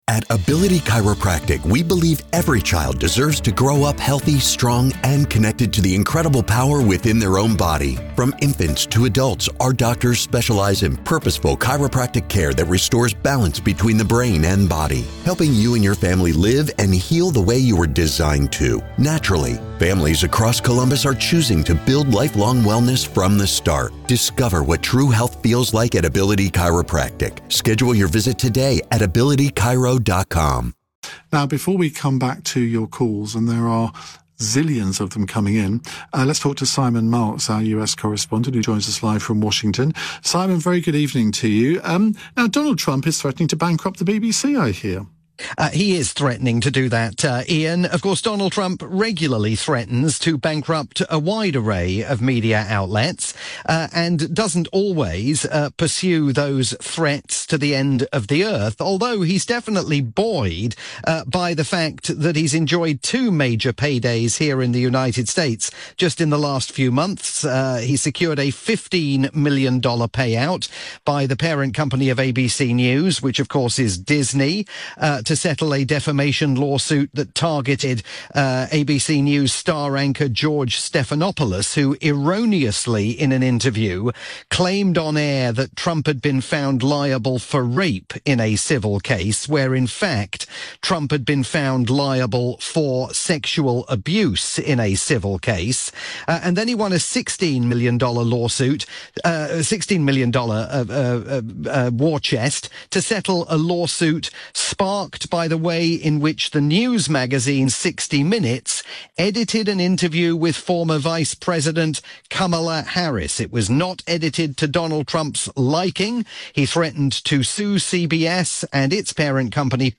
live update for Iain Dale's evening programme on the UK's LBC.